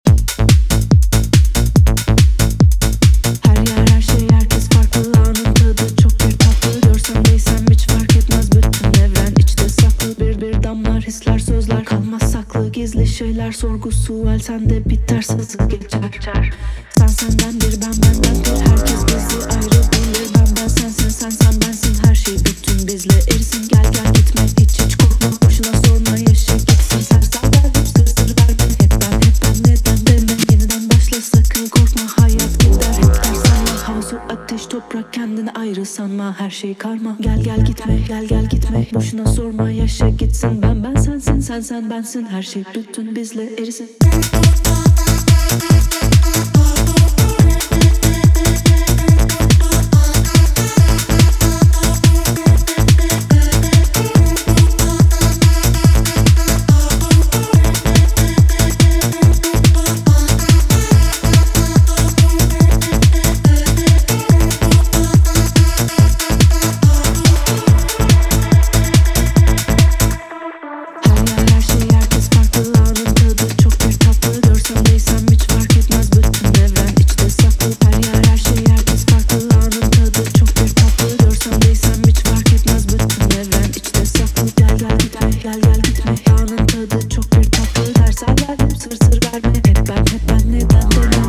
パリッとデジタルな質感のトラックに催眠的フィメールヴォーカルが気分を盛り上げる